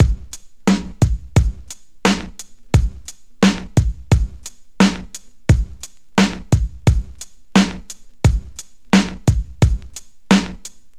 • 87 Bpm 90's Rap Drum Beat B Key.wav
Free breakbeat - kick tuned to the B note. Loudest frequency: 842Hz
87-bpm-90s-rap-drum-beat-b-key-vDv.wav